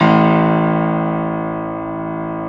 53d-pno01-A-1.wav